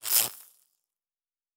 Sci-Fi Sounds / Electric / Spark 01.wav
Spark 01.wav